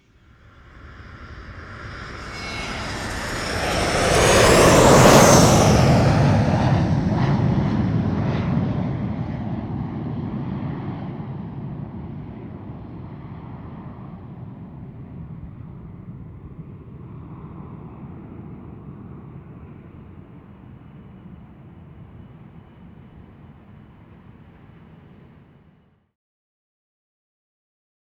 jetGoing.wav